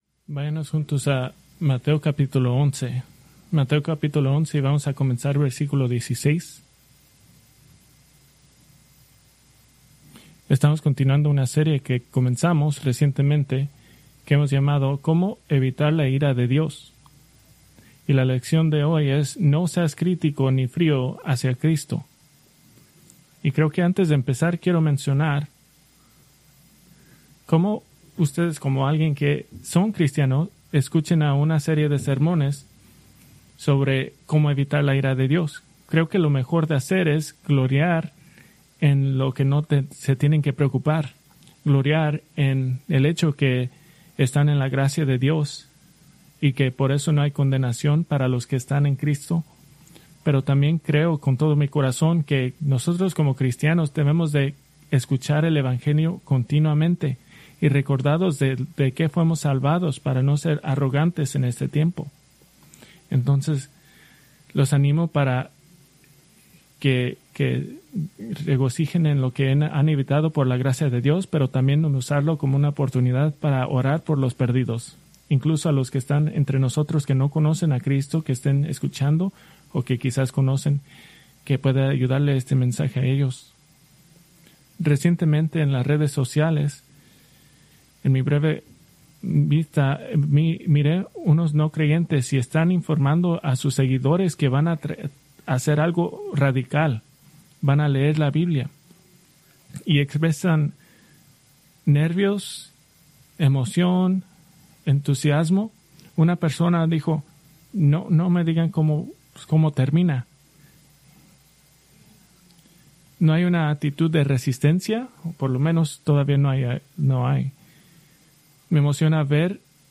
Preached October 12, 2025 from Mateo 11:16-24